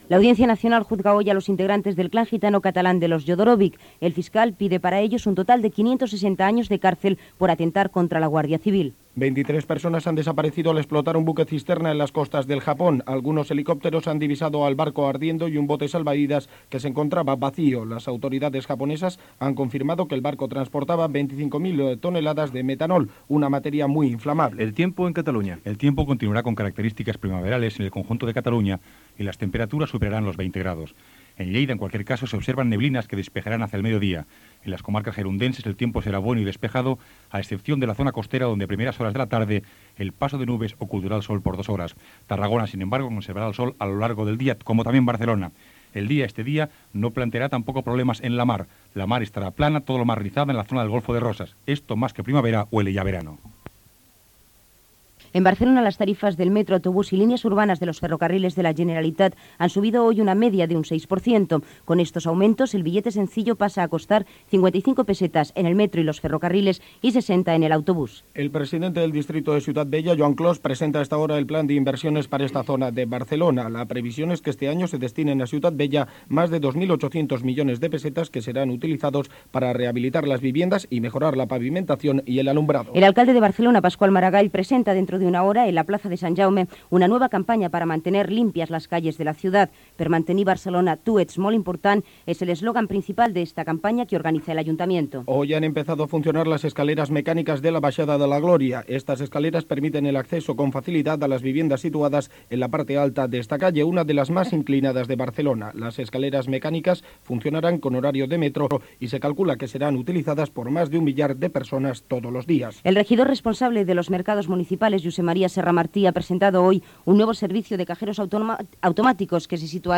Gènere radiofònic Informatiu
Programa pilot de la radiofórmula Barcelona Cadena Catalana (BCC).